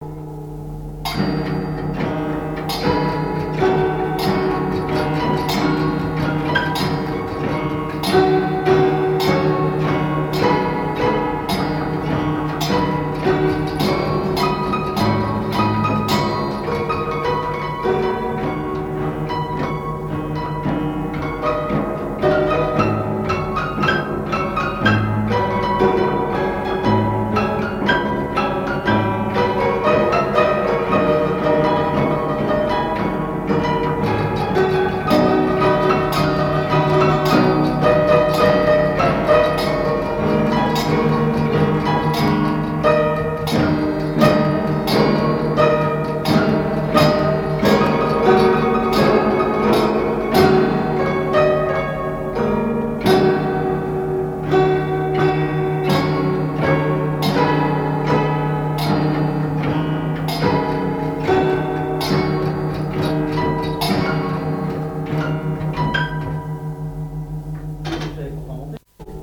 danse : valse
Pièce musicale inédite